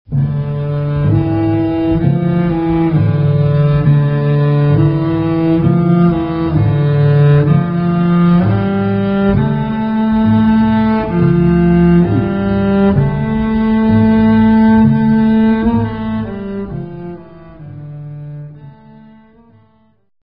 La Contrebasse
contrebasse_orchestre.mp3